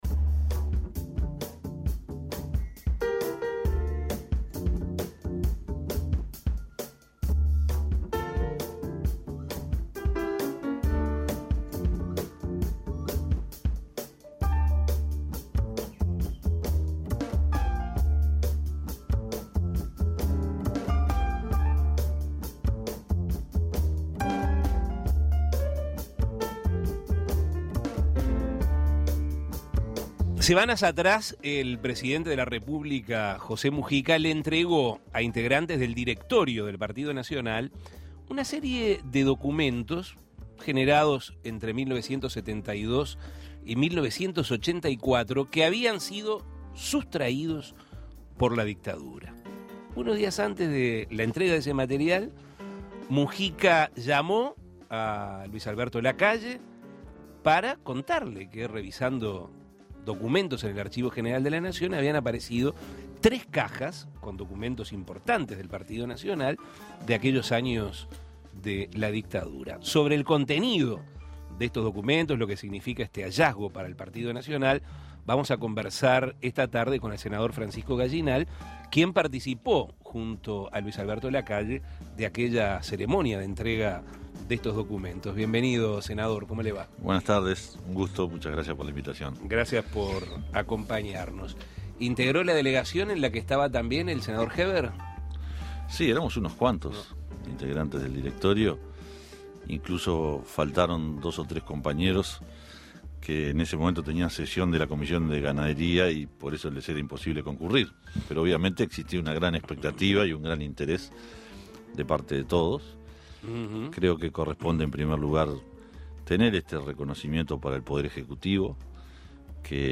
Francisco Gallinal, senador de este partido político dialogó sobre lo que significa este hallazgo para la agrupación nacionalista y el contenido de estos documentos, generados entre los años 1972 y 1984, y que habían sido sustraídos por la dictadura. También, habló sobre el monumento a Wilson ubicado en la Explanada de la Intendencia de Montevideo. Escuche la entrevista.